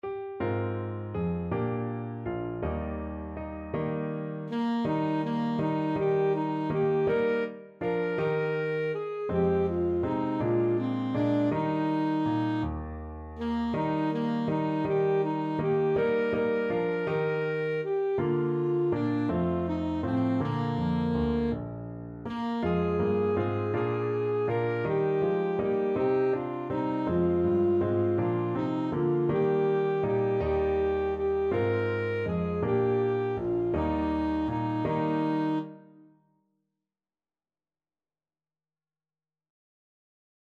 Alto Saxophone version
Alto Saxophone
3/4 (View more 3/4 Music)
One in a bar .=c.54
Bb4-Bb5
Traditional (View more Traditional Saxophone Music)